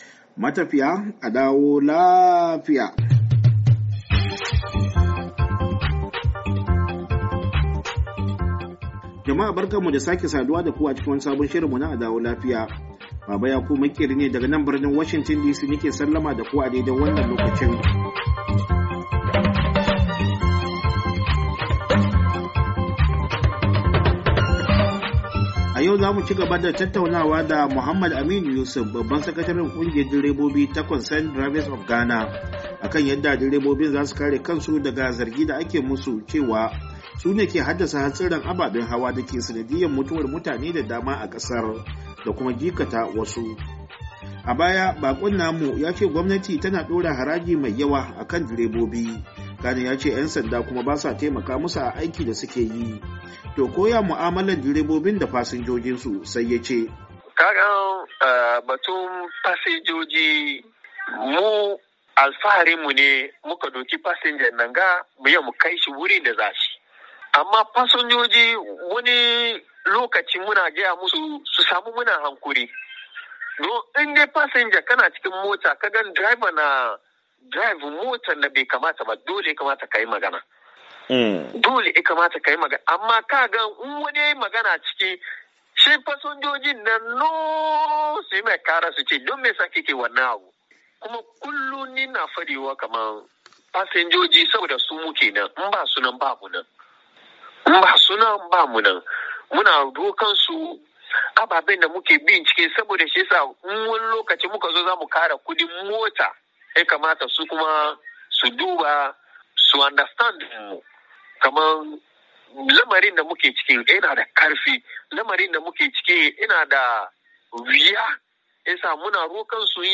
Cigaban tattaunawa